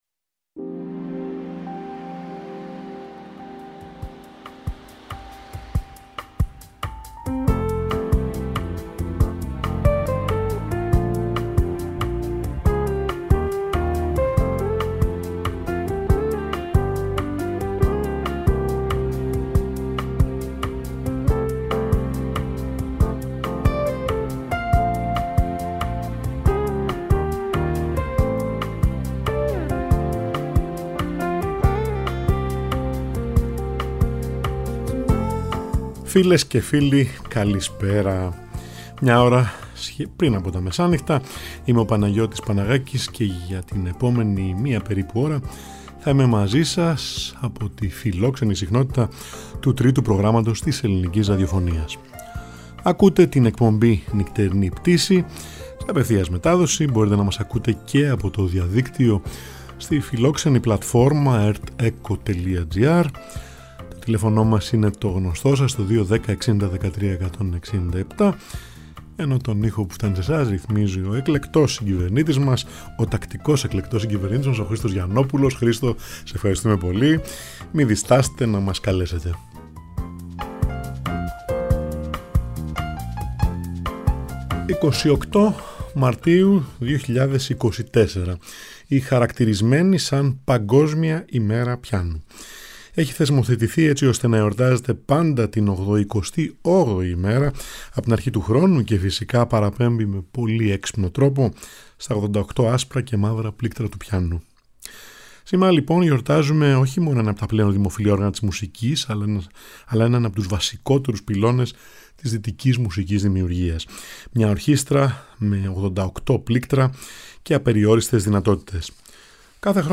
Κοντσερτα για Πιανο